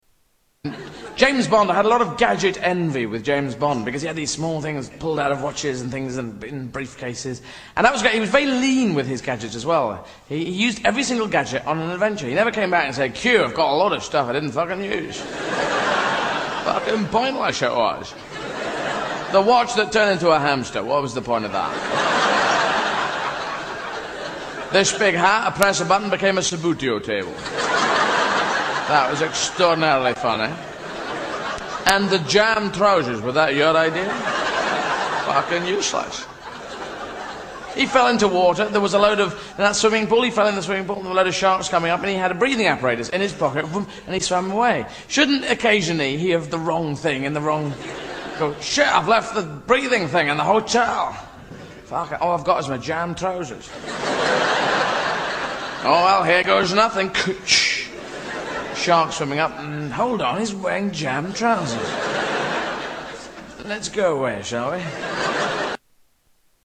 Category: Comedians   Right: Personal
Tags: Comedians Eddie Izzard Eddie Izzard Soundboard Eddie Izzard Clips Stand-up Comedian